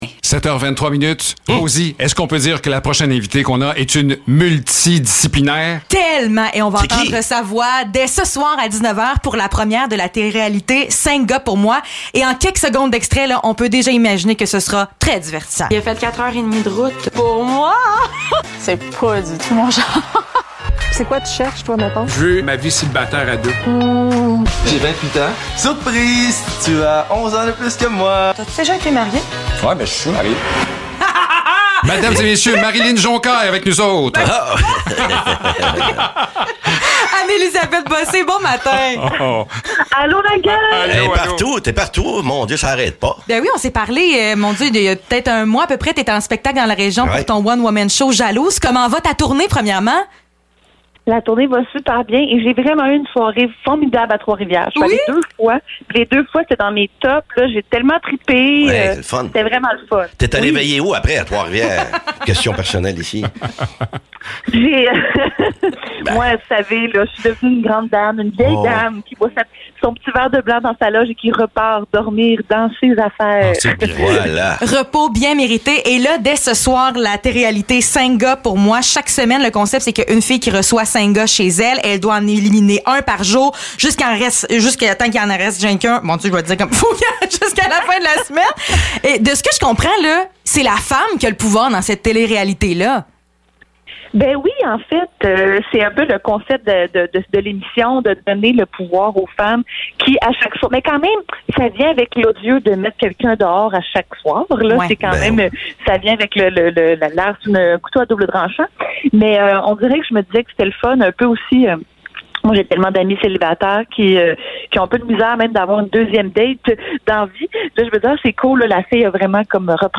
Entrevue avec Anne-Élisabeth Bossé (11 avril 2022)